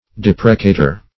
Search Result for " deprecator" : The Collaborative International Dictionary of English v.0.48: Deprecator \Dep"re*ca`tor\ (d[e^]p"r[-e]*k[=a]`t[~e]r), n. [L.] One who deprecates.